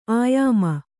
♪ āyāma